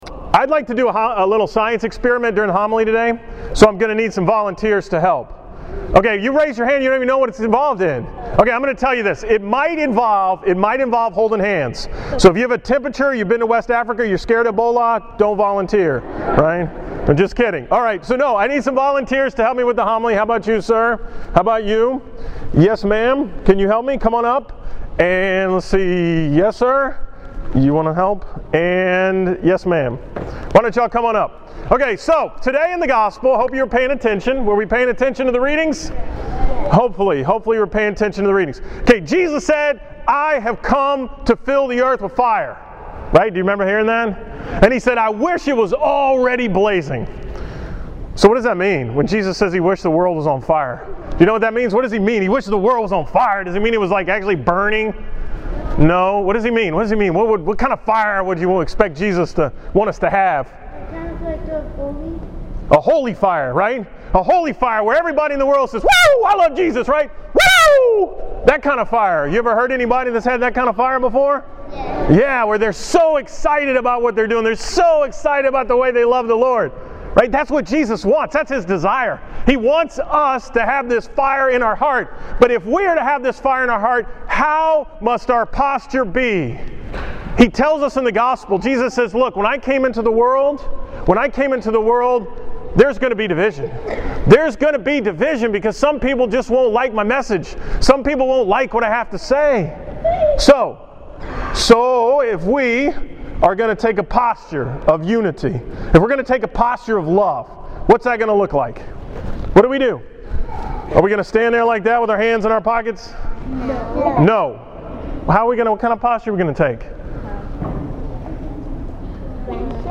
From the school Mass on October 23, 2014 at St. Rose of Lima